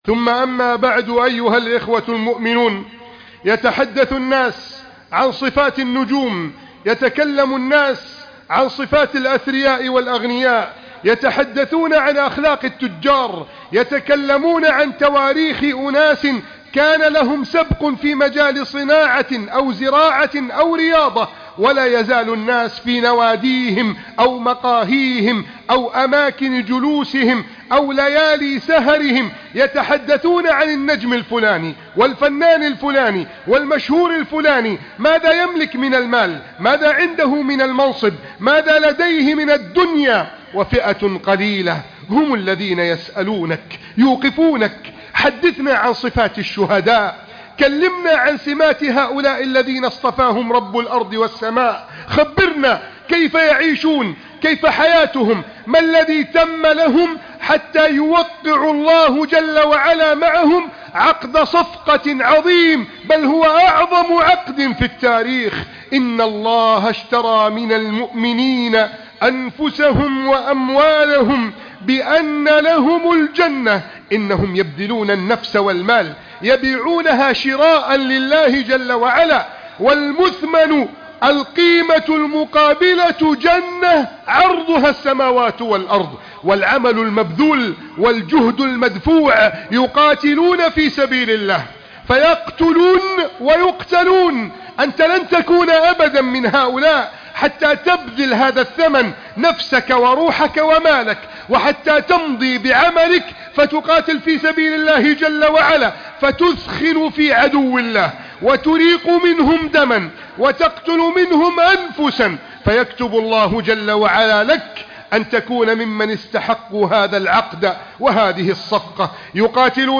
أبطال غزة أقوى و أحدث خطبة جمعة